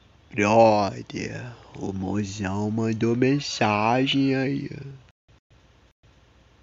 Categoria: Toques